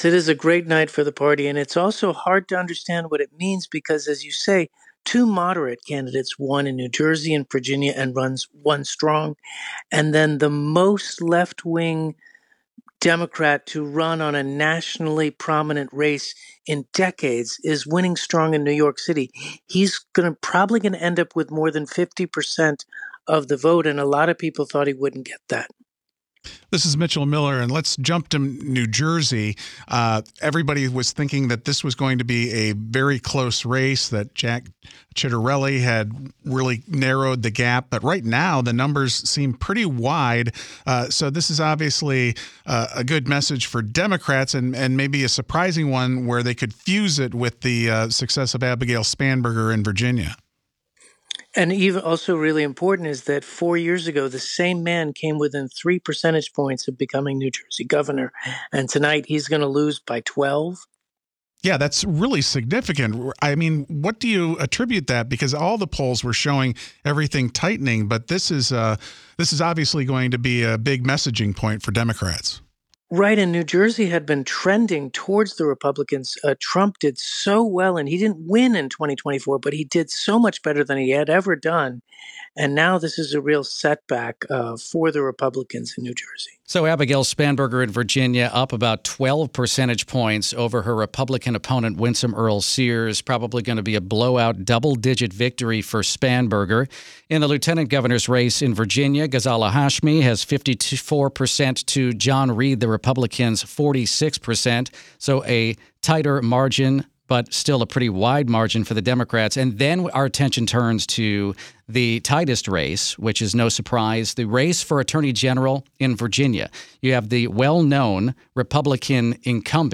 joined WTOP to discuss the blue wave that lifted Democrats on Election Day.